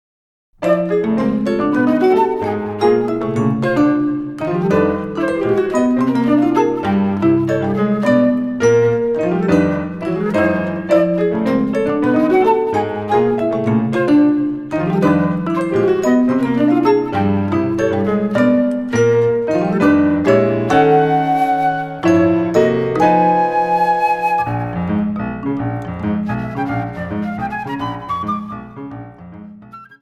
ジャズというよりも現代音楽に近い曲想だ。